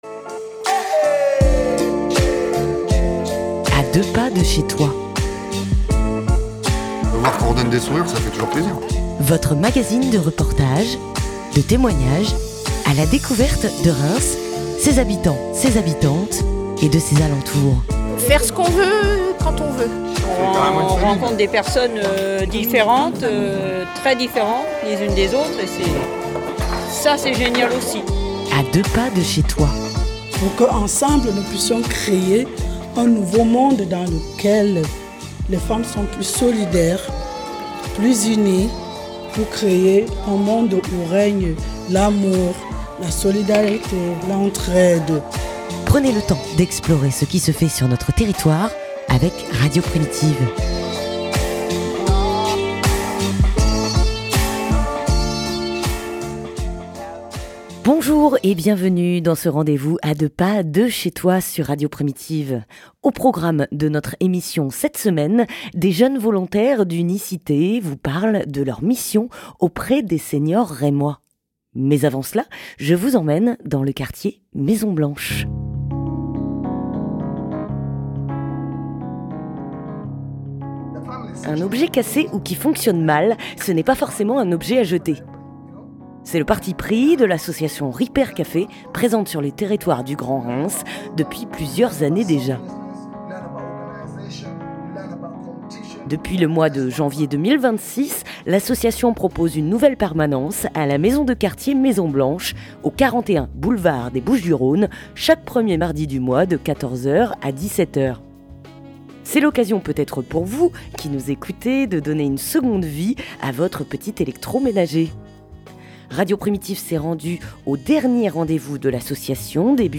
Radio Primitive est allée les rencontrer sur une des premières permanences.
(de 00:00 à 16:00) Nous avons également reçu les volontaires de l' association Unicité qui vous présentent leurs missions auprès des seniors Rémois .